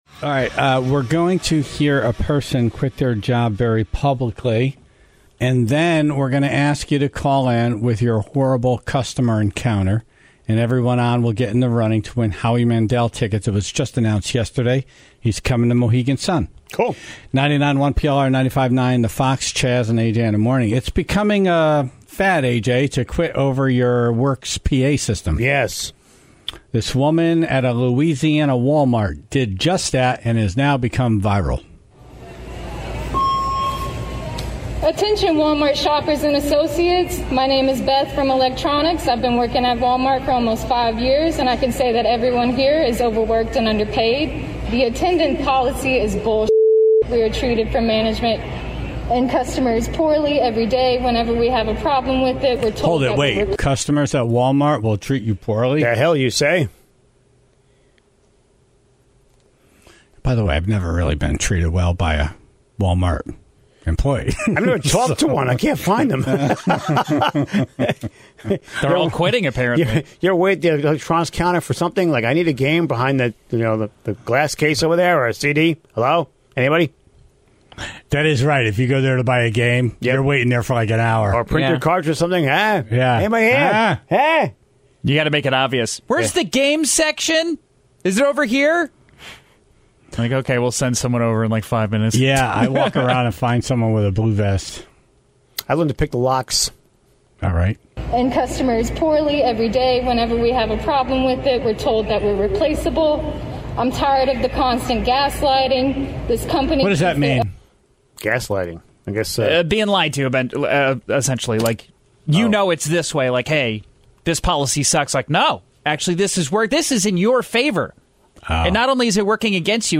Then, they asked the Tribe to call in their stories with terrible customers, since that seemed to be part of the reason this person walked out.